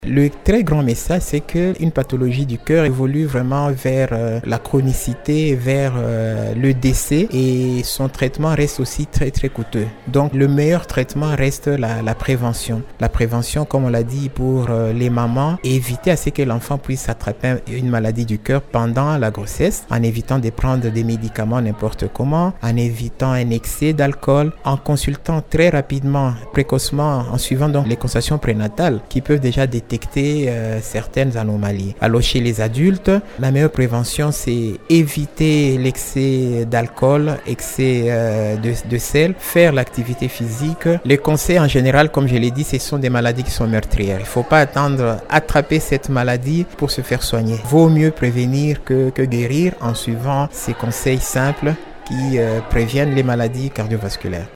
Santé